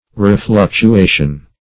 refluctuation.mp3